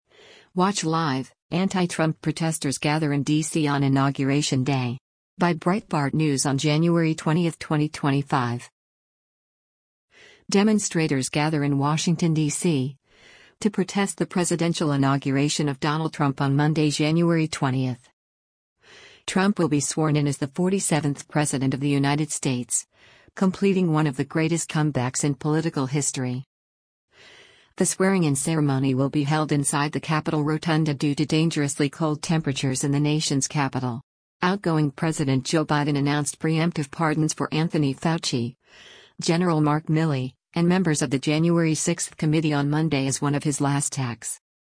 Demonstrators gather in Washington, DC, to protest the presidential inauguration of Donald Trump on Monday, January 20.